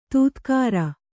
♪ tūtkāra